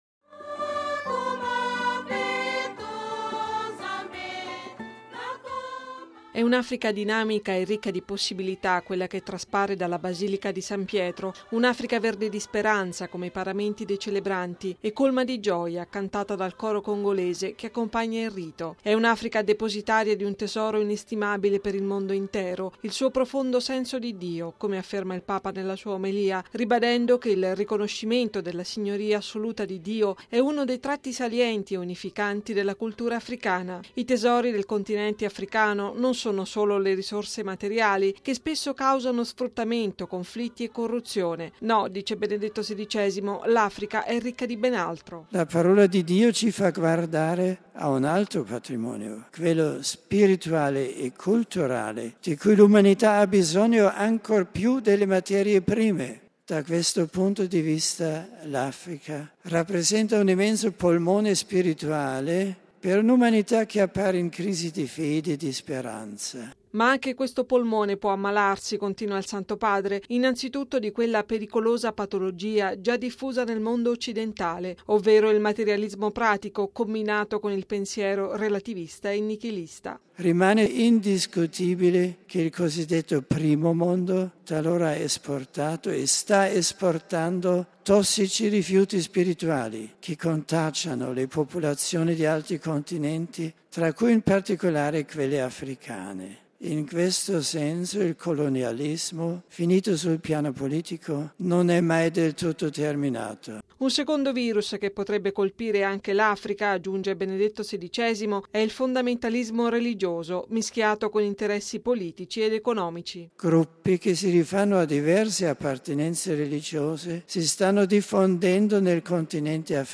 (canto: Nakoma Peto)
(canto: Ee Mfumu, yamba makabu)